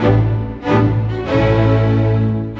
Rock-Pop 06 Strings 01.wav